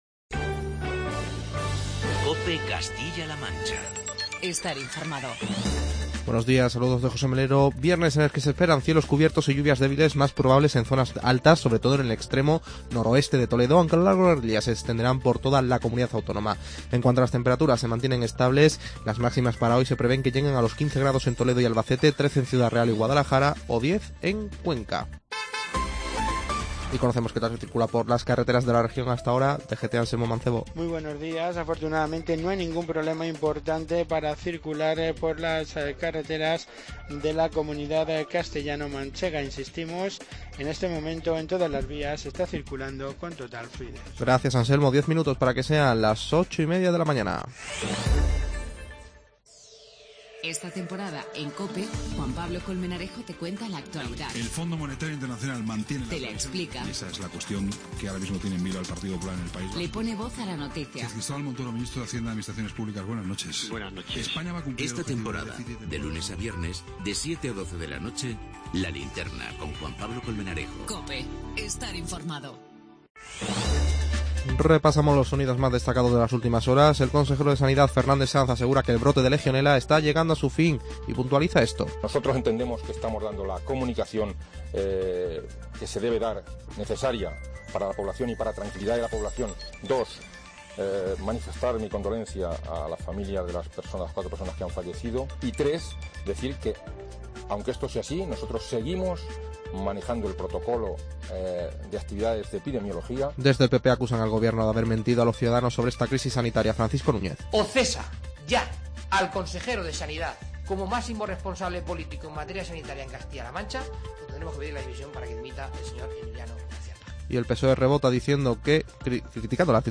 Informativo regional y provincial
Repasamos los sonidos más destacados de las últimas horas.